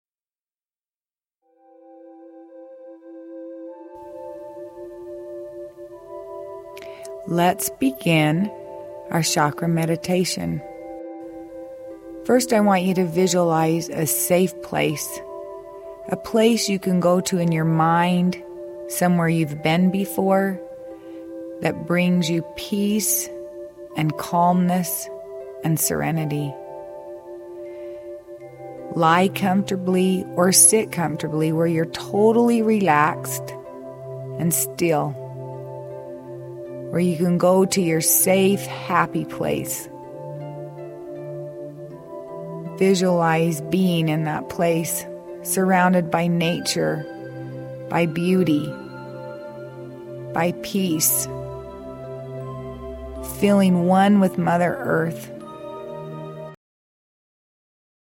Guided Beginners Meditation
To enhance your healing experience, the accompanying music to this guided meditation is composed in each of the chakras’ individual musical notes.